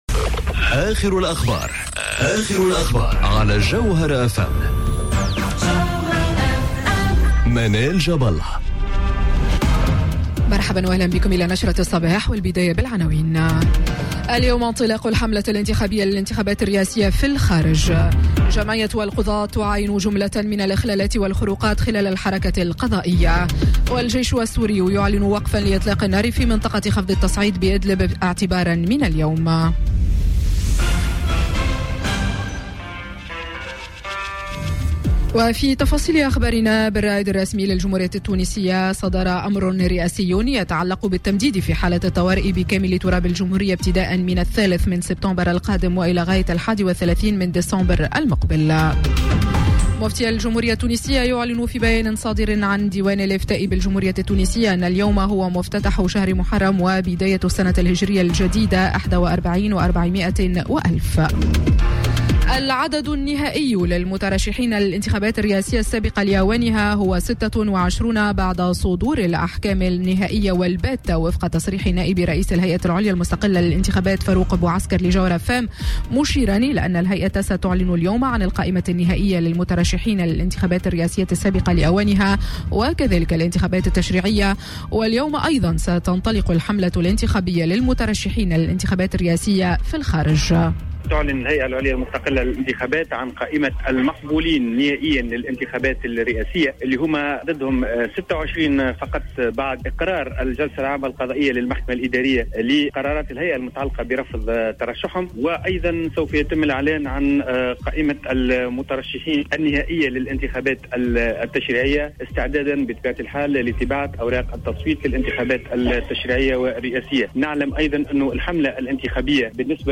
نشرة أخبار السابعة صباحا ليوم السبت 31 أوت 2019